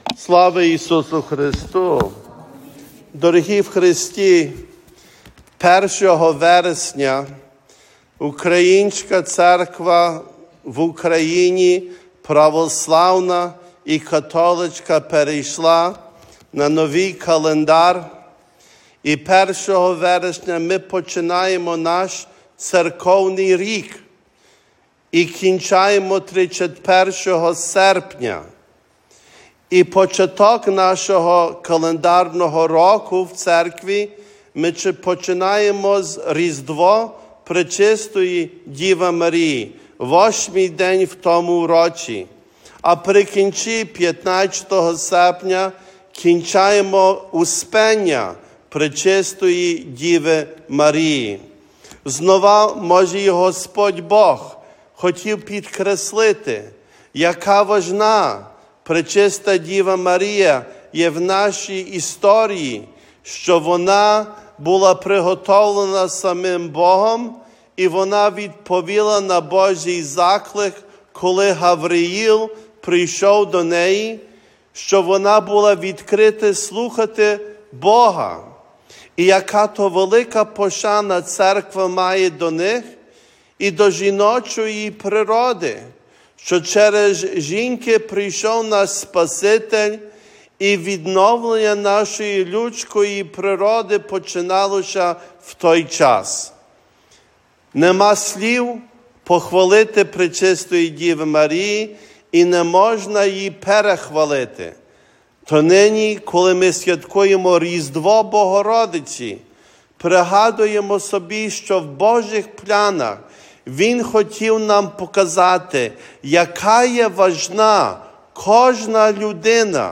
Audio Archive of Weekly Sermons - 2023 / Аудіо архів щотижневих проповідей - 2023